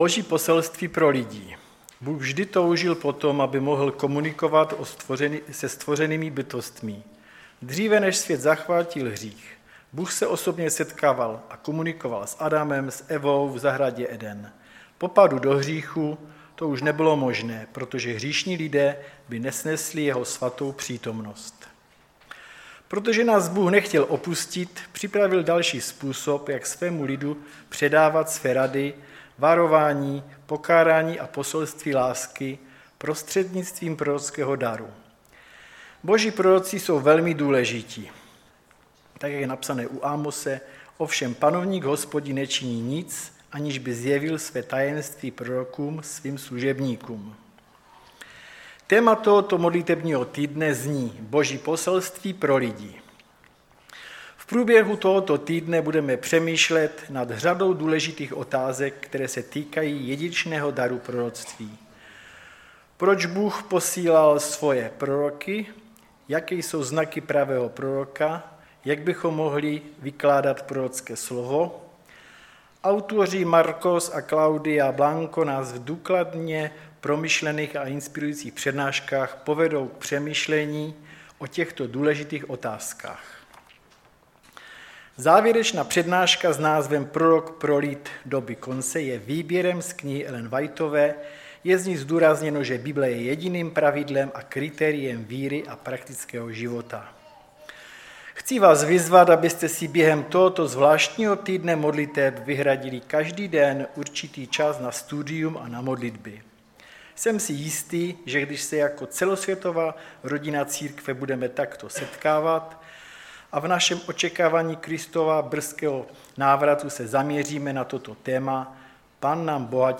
Sbor Ostrava-Radvanice. Shrnutí přednášky začíná ve 14-té minutě.